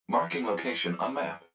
042_Marking_Location.wav